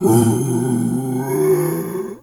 pgs/Assets/Audio/Animal_Impersonations/bear_roar_soft_09.wav at master
bear_roar_soft_09.wav